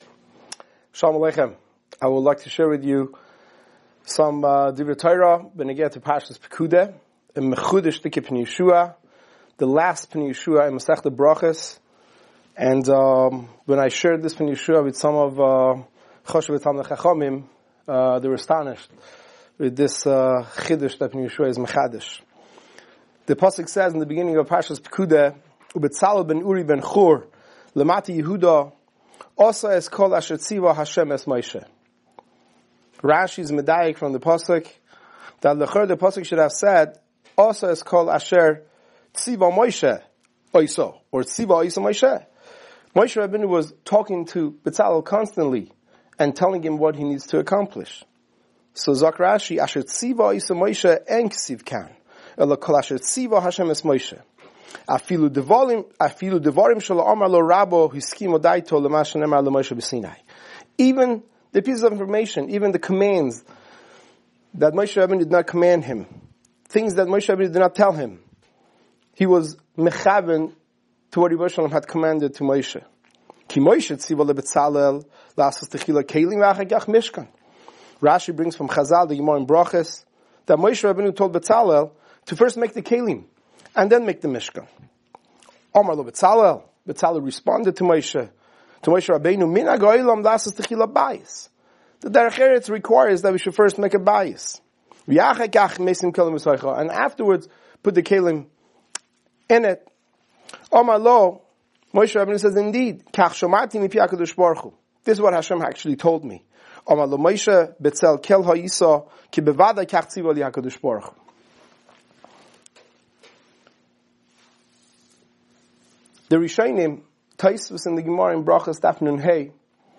Weekly Alumni Shiur Pekudei 5785